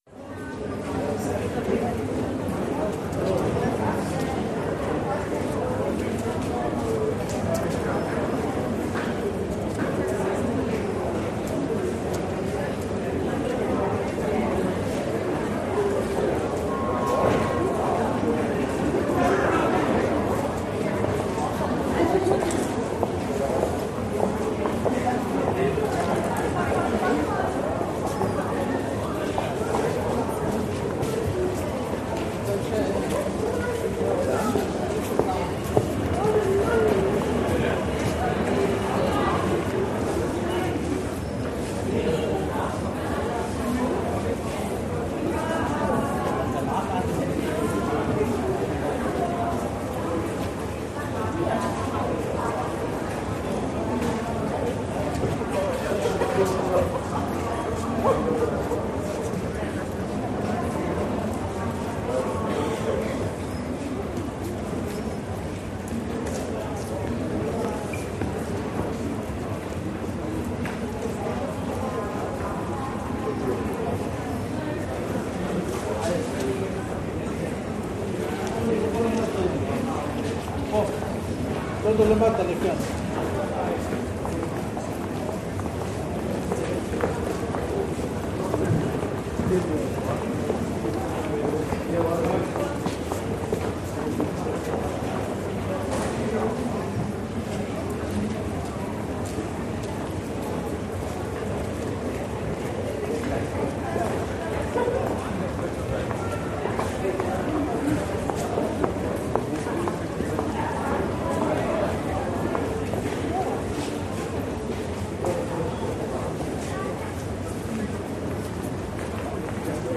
S Bahn - Hum of Voices Shops
Tags: U Bahn S Bahn Berlin Berlin subway Subway sounds